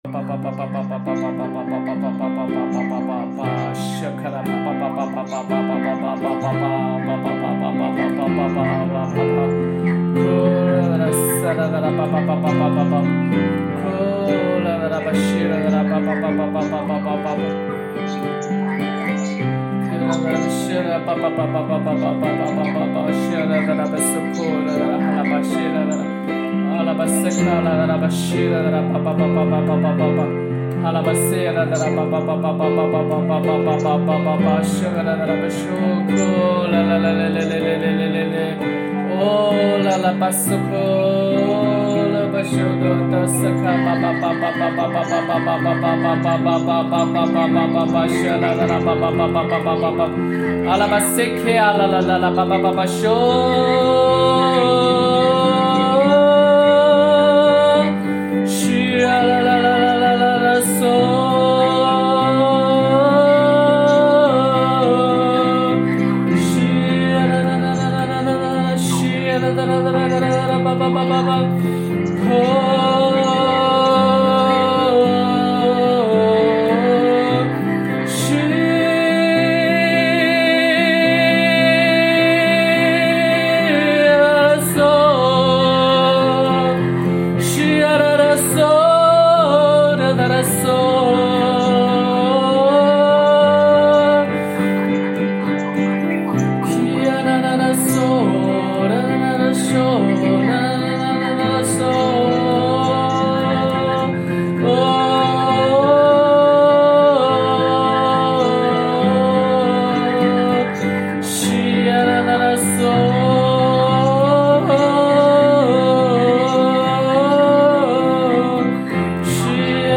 启示性祷告：